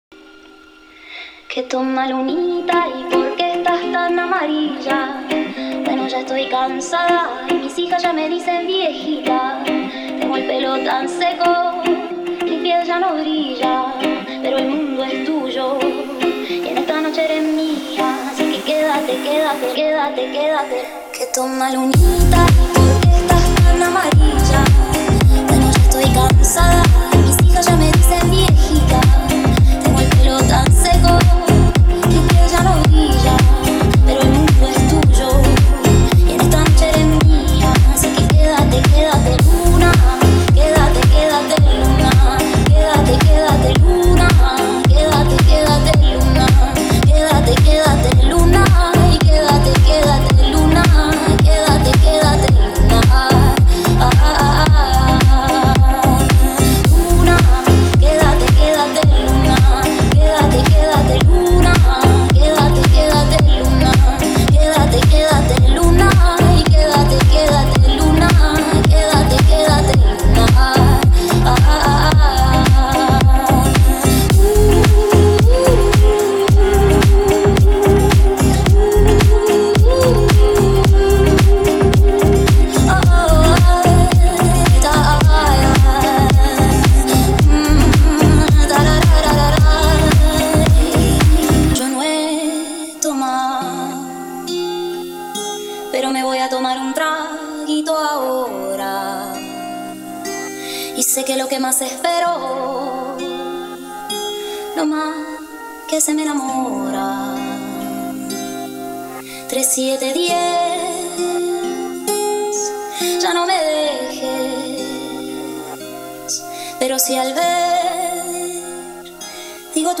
это яркая и мелодичная композиция в жанре латин-поп